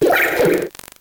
Cri de Doudouvet dans Pokémon Noir et Blanc.